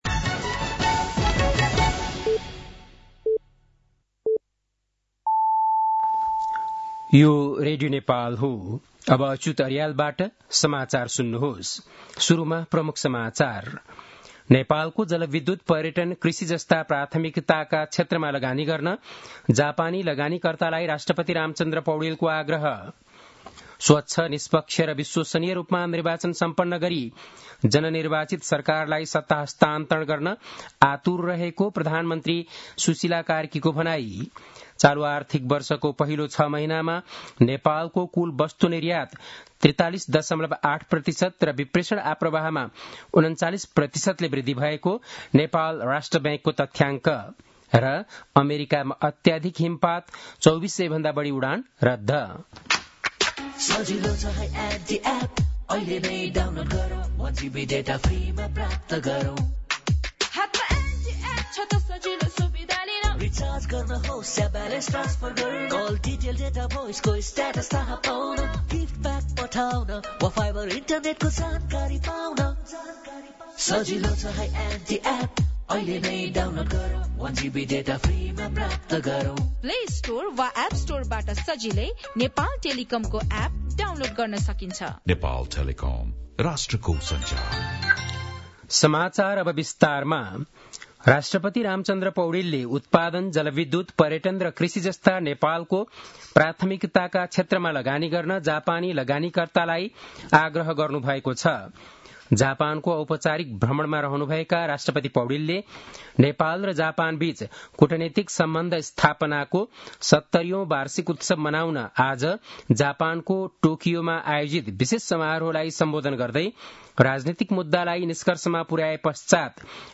बेलुकी ७ बजेको नेपाली समाचार : १९ माघ , २०८२
7-pm-nepali-news-10-19.mp3